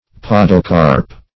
Podocarp \Pod"o*carp\, n. [Podo- + Gr. karpo`s fruit.] (Bot.)